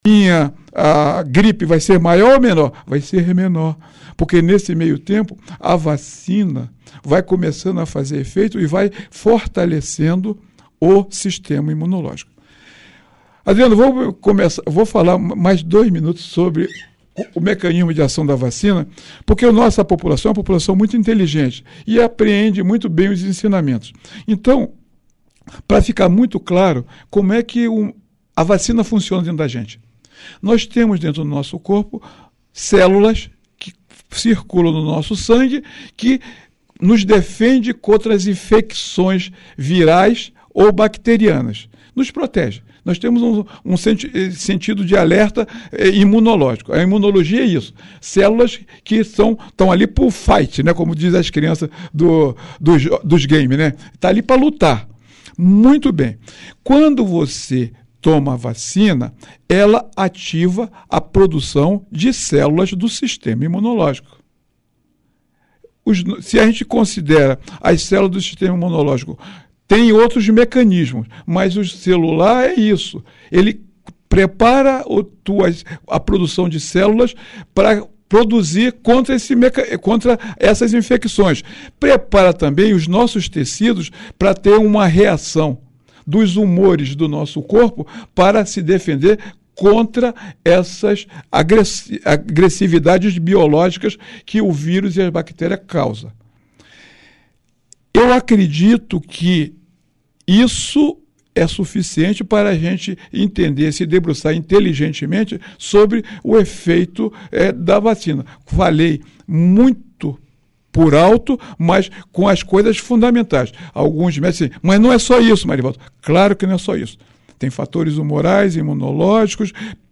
O Secretário de Saúde, Marivaldo dos Reis, esteve ao vivo no Jornal Colmeia dessa quinta-feira, 02, e disse que o índice de vacinação não chegou nem a 50% do público alvo.
Você pode acompanhar o áudio com a entrevista completa do Secretário de Saúde abaixo: